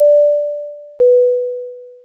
ceinture_on.wav